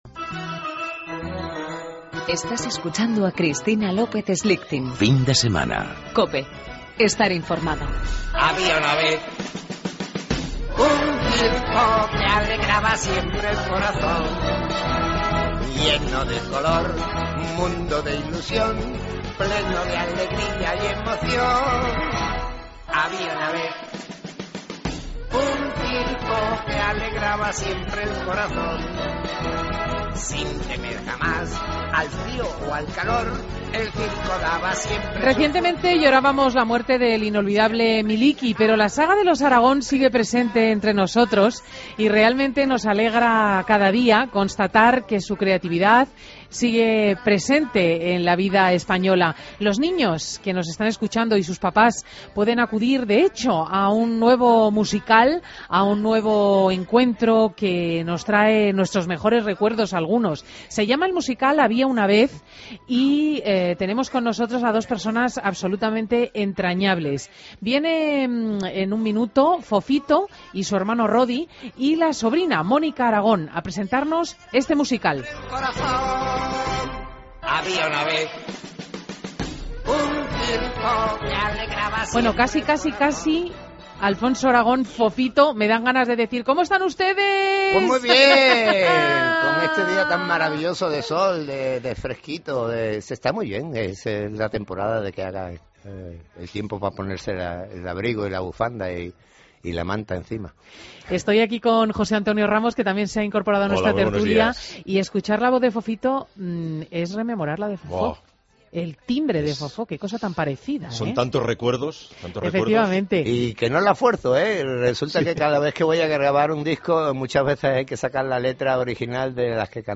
Entrevista a Fofito